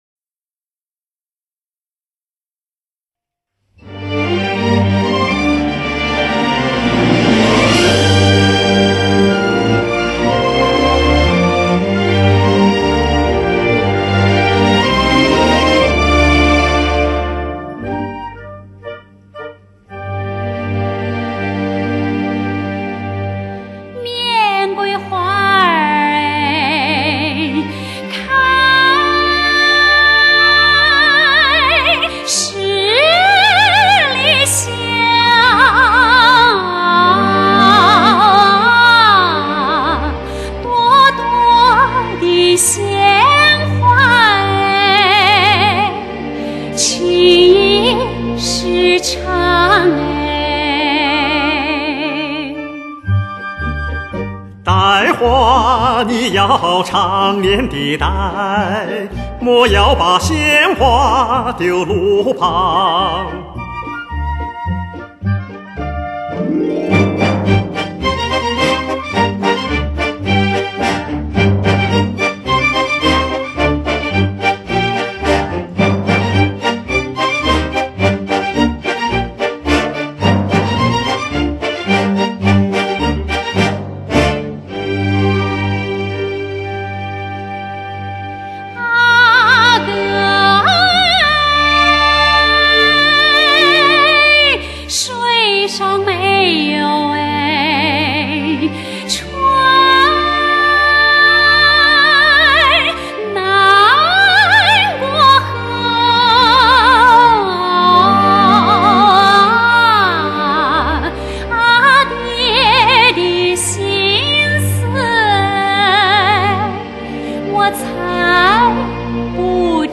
近乎完美的音色和无可挑剔的声音
顶级的美国和香港DTS制作班底，精心设计最出色的环绕声效，全面为你呈现最震撼的声音盛宴！